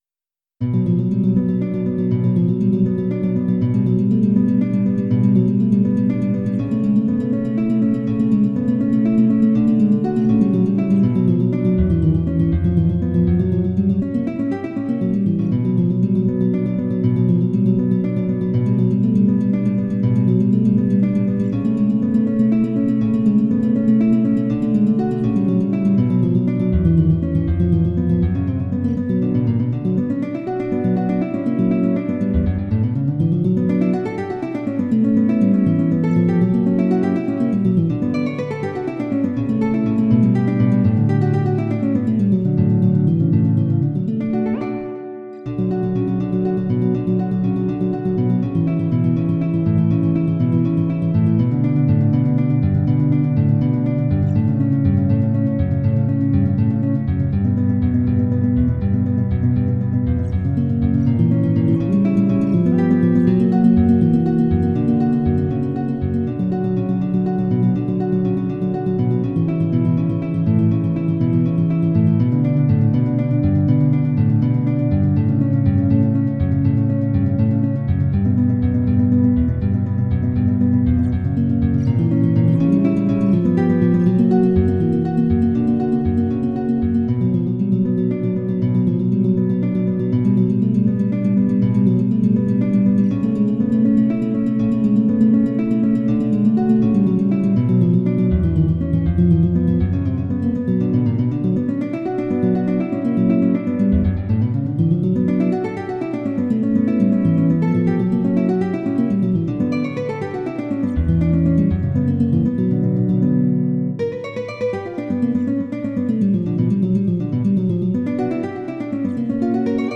�l�h�c�h�@�N���V�b�N�M�^�[�@�u�吹���v���u���d�ȃA���O���v (Allegro solemne)